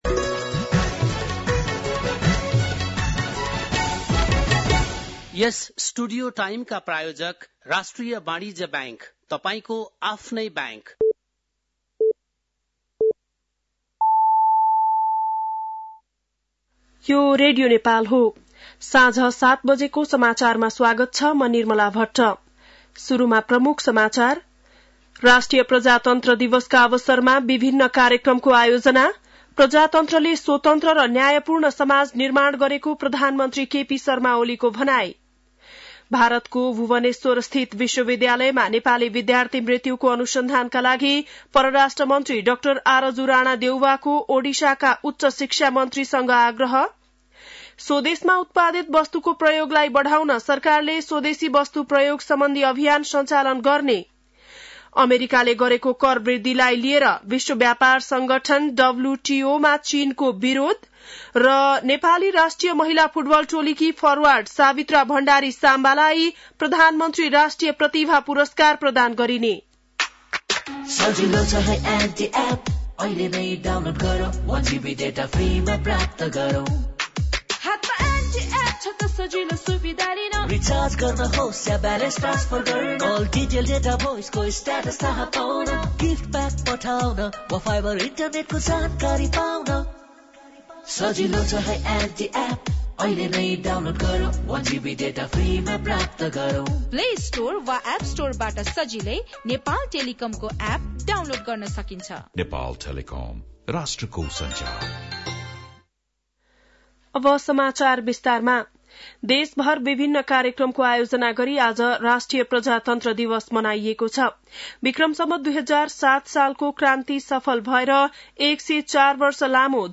बेलुकी ७ बजेको नेपाली समाचार : ८ फागुन , २०८१
7-PM-Nepali-NEWS-11-07.mp3